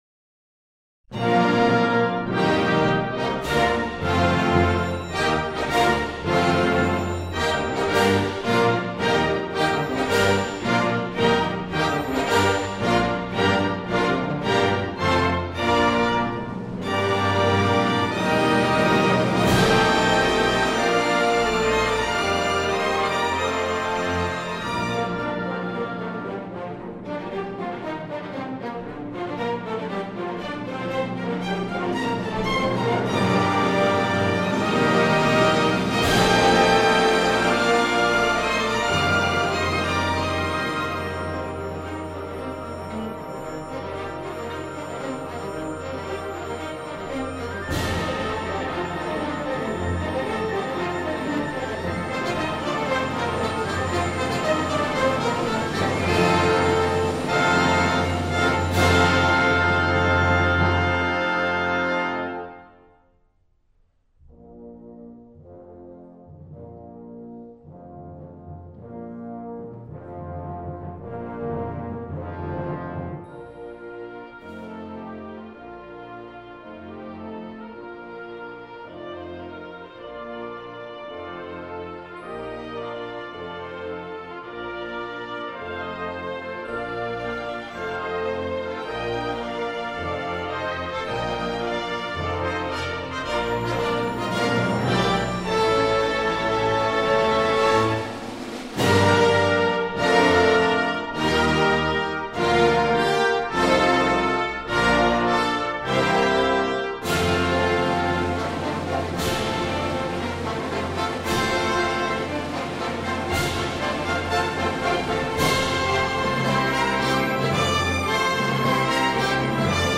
march.mp3